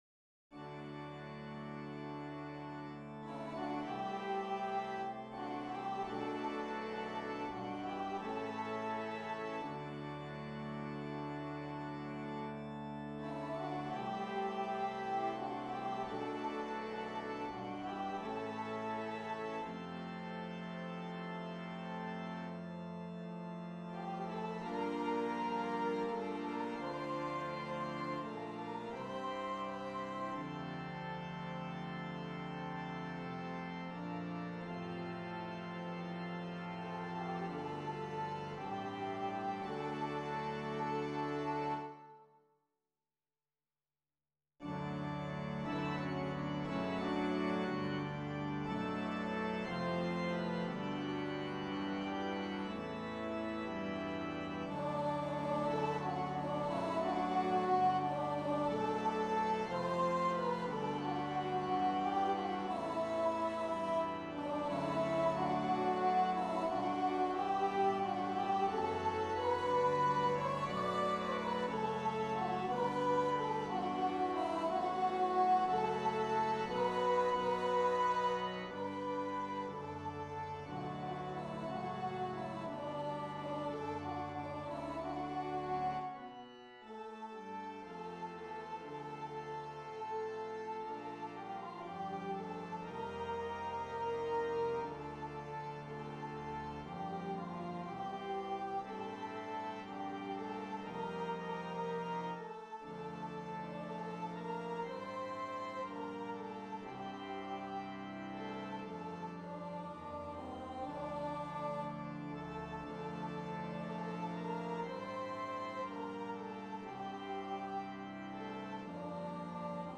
Celebrant, Congr.+org